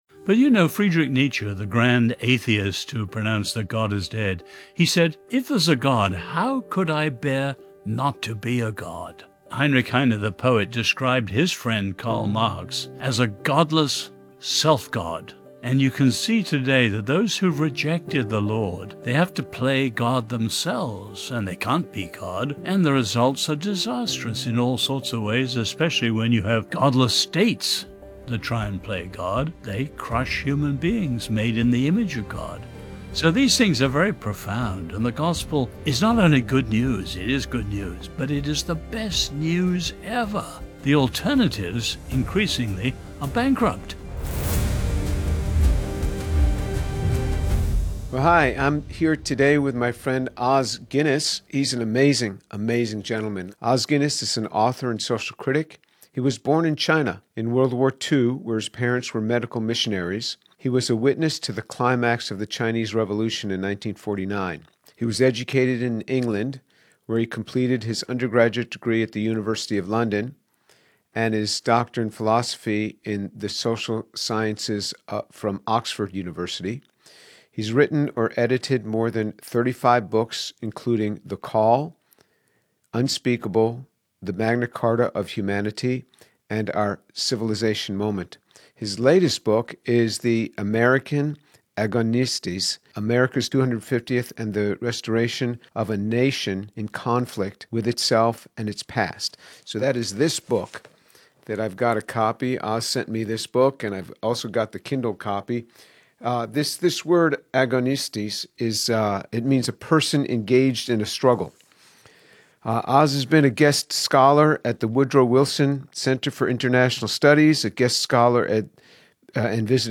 Dr. James Tour interviews author and social critic Os Guinness about American Agonistes.